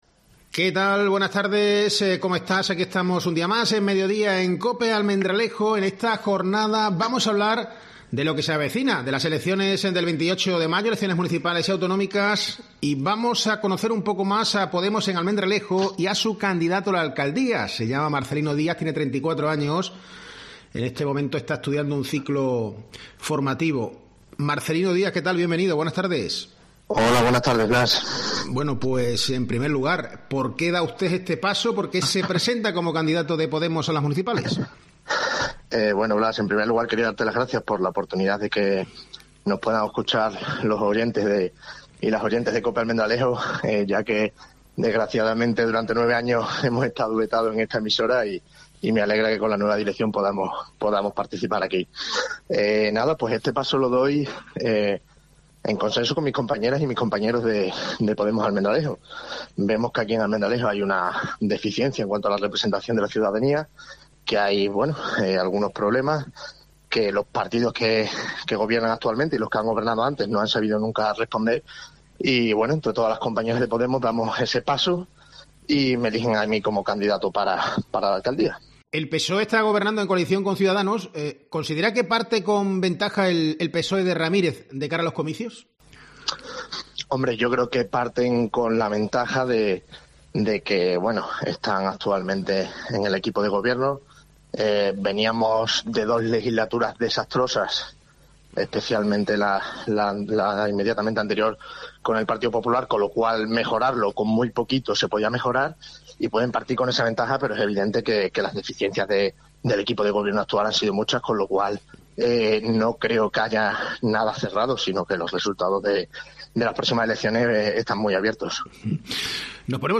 A preguntas de COPE, hace hincapié en que Ramírez “es un político profesional: promete cosas que no cumple” y le afea que haya sido “el alcalde que más ha destinado a la tauromaquia”.